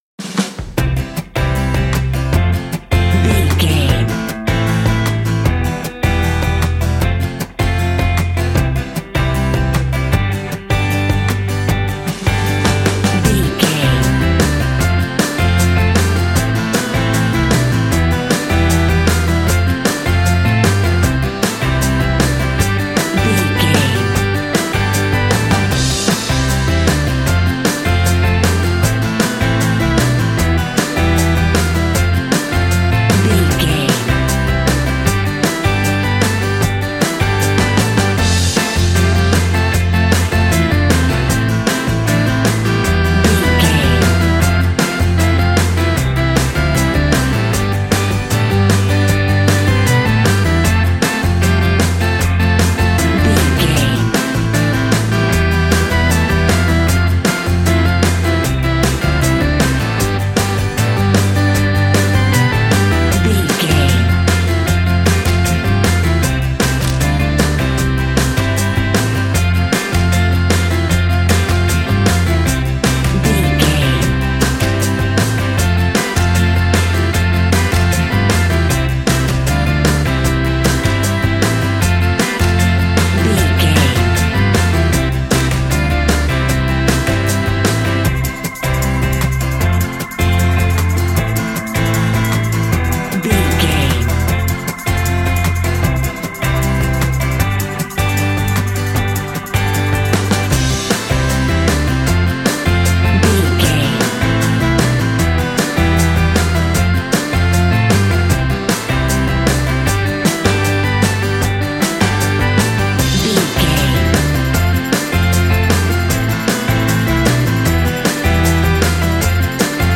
Ionian/Major
cool
uplifting
bass guitar
electric guitar
drums
cheerful/happy